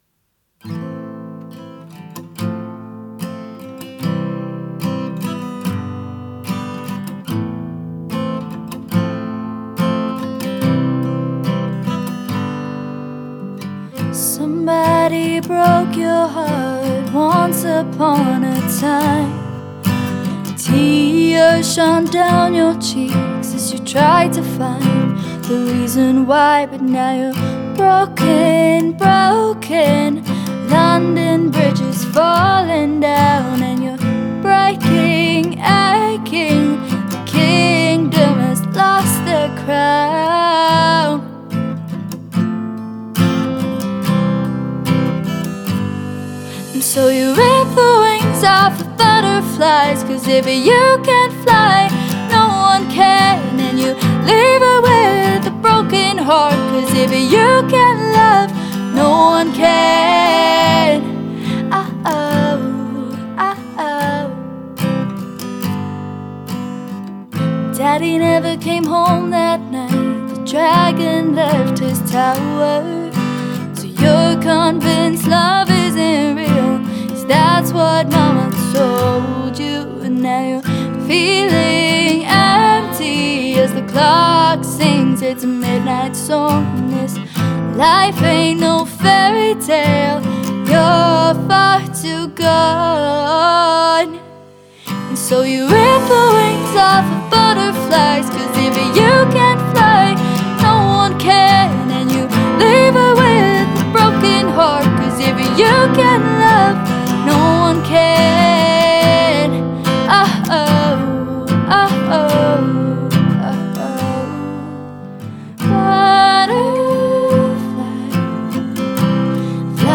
This is a snippet of my original song recorded last week
Butterfly wings Rough Mix.mp3